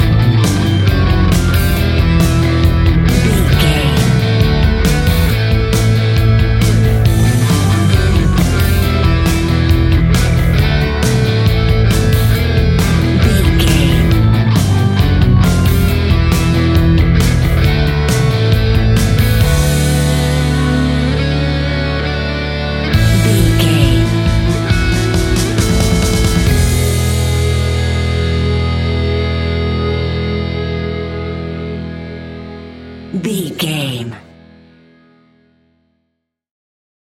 Epic / Action
Fast paced
Ionian/Major
guitars
hard rock
distortion
punk metal
instrumentals
Rock Bass
Rock Drums
heavy drums
distorted guitars
hammond organ